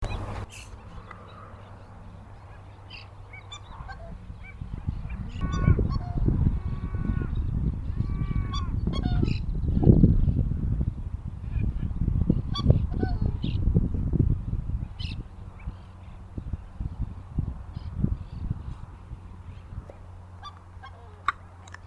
Coscoroba (Coscoroba coscoroba)
Pareja donde uno de ellos vocalizaba!
Nombre en inglés: Coscoroba Swan
Localidad o área protegida: Ceibas
Condición: Silvestre
Certeza: Observada, Vocalización Grabada